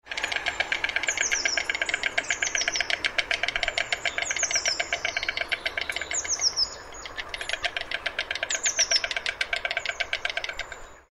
На этой странице собраны звуки аиста в разных ситуациях: крики, щелканье клювом, шум крыльев.
Стук аистиных клювов